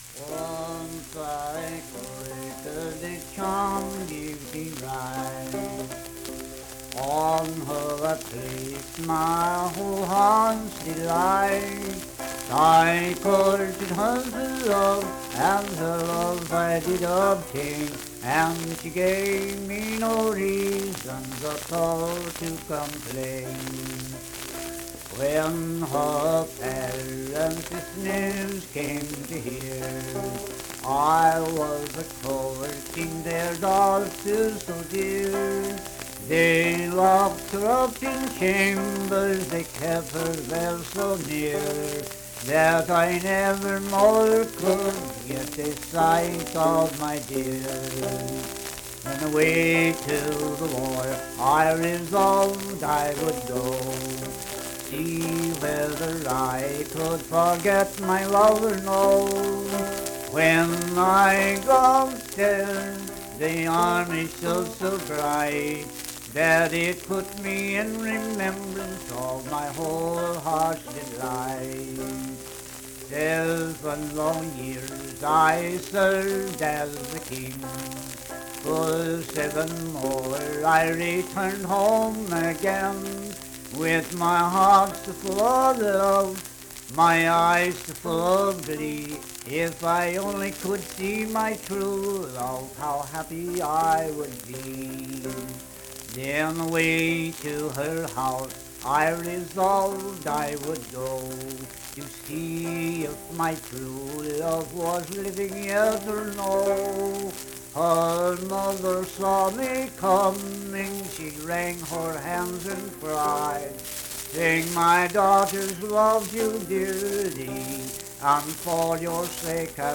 Banjo accompanied vocal music performance
Verse-refrain 7(4).
Voice (sung), Banjo